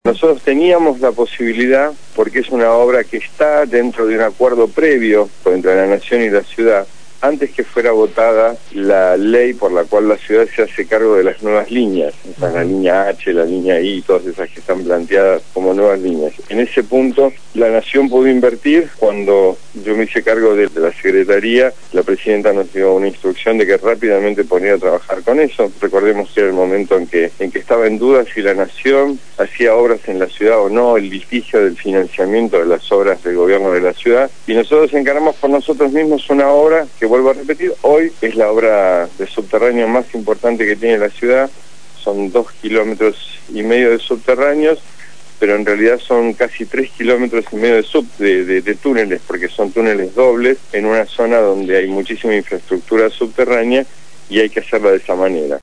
Lo dijo Juán Pablo Schiavi, secretario de Transporte de la Nación, en el programa «Punto de partida» (Lunes a viernes de 7 a 9 de la mañana), por Radio Gráfica FM 89.3